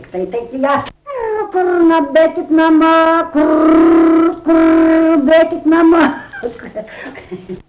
daina, vaikų
Erdvinė aprėptis Obeliai
Atlikimo pubūdis vokalinis